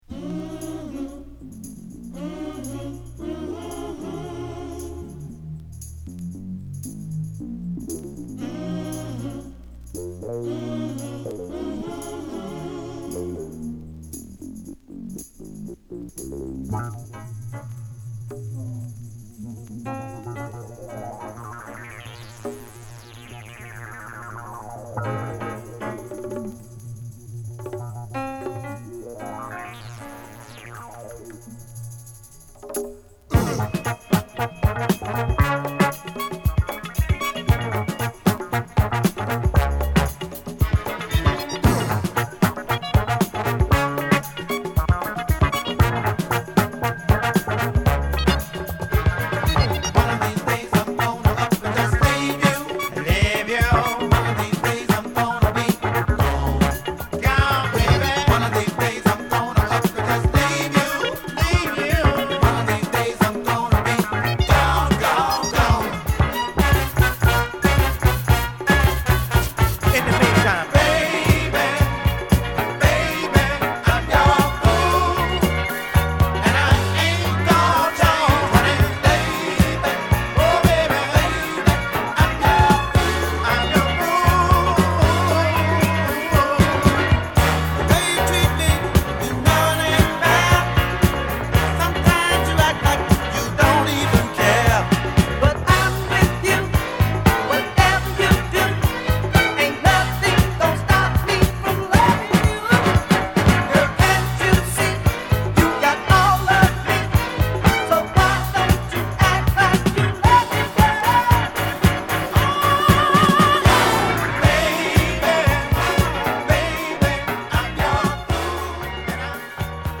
ストレートなフィリー・ソウル作品です！